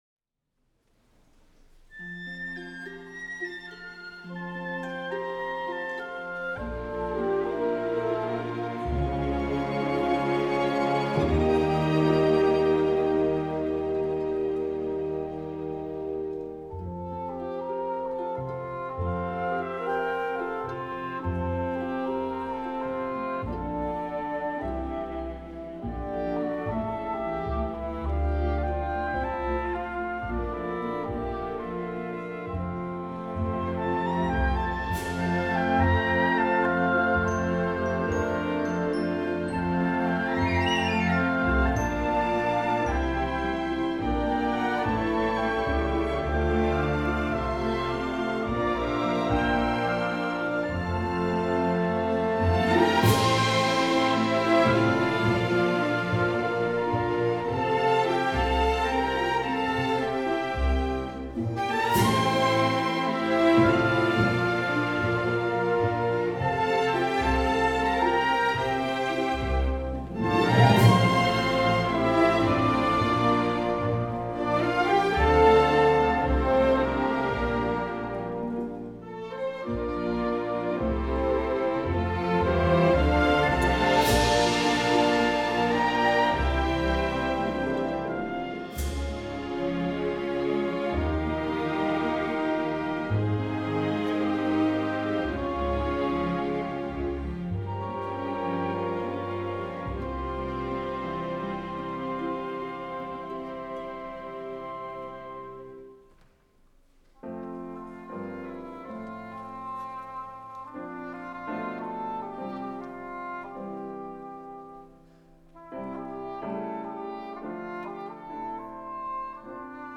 An orchestra medley